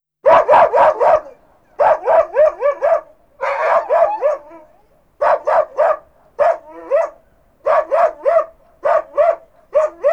barking.wav